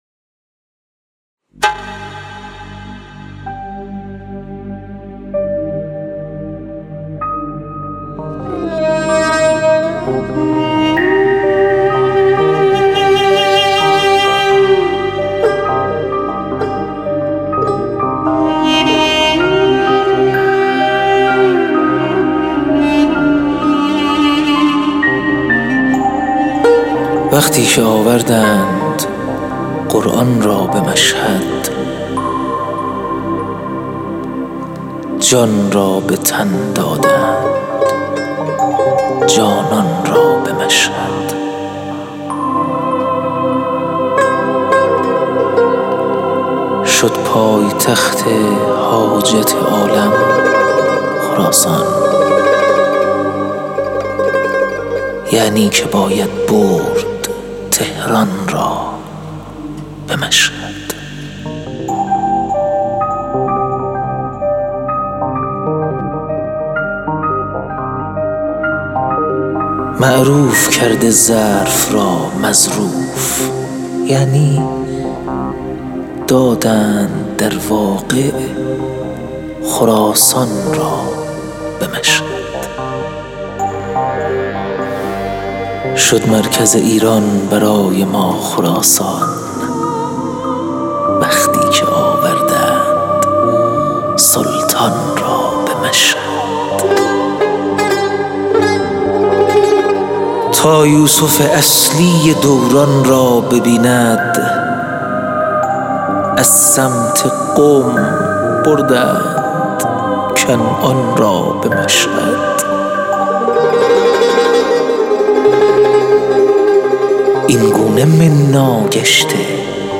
دکلمه‌‌ای در رثای امام رضا (ع) صوت - تسنیم
صوت این دکلمه در ادامه آمده است: